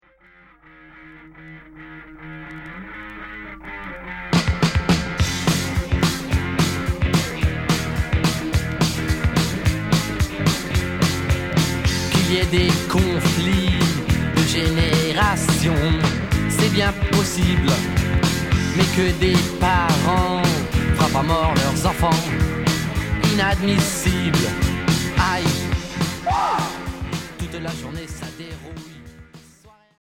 Rock punk